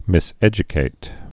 (mĭs-ĕjə-kāt)